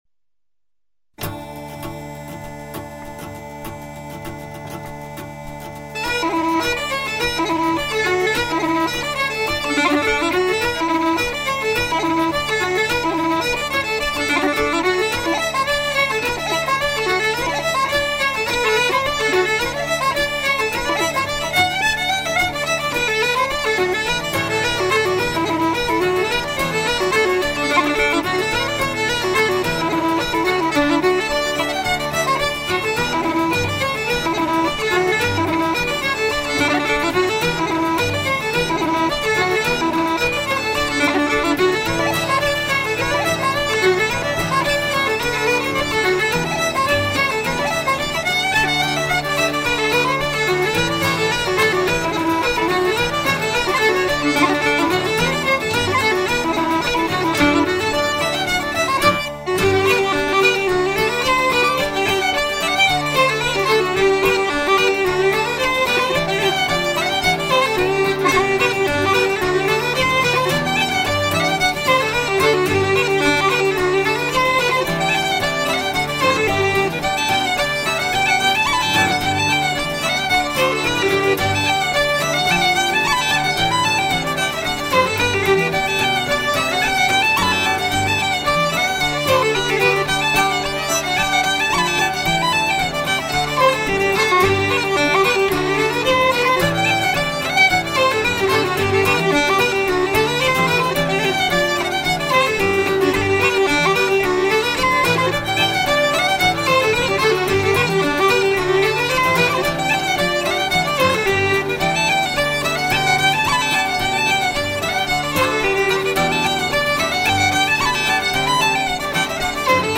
Fiddle
Uilleann pipes
mp3:  Reels